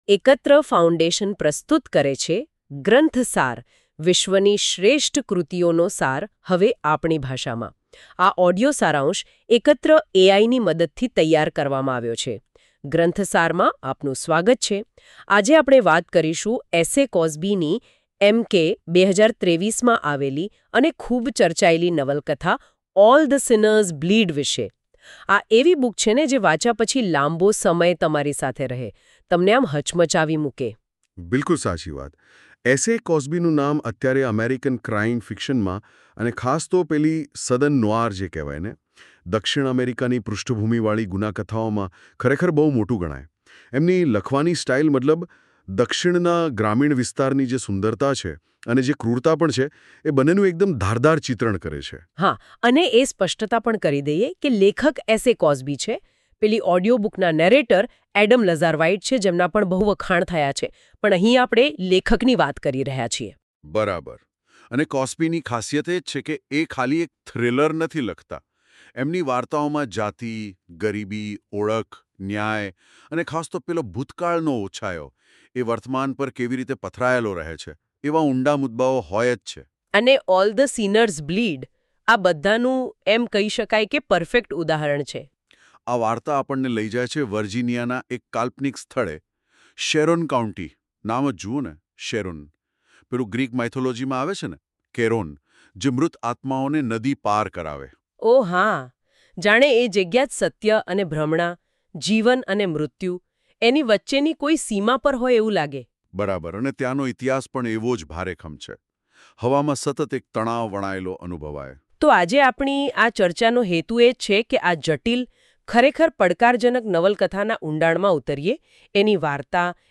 Category : Ekatra audio summary – Gujarati